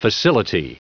Prononciation du mot facility en anglais (fichier audio)
Prononciation du mot : facility